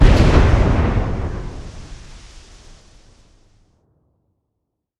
Звуки детонатора
Дополнительный взрывной импульс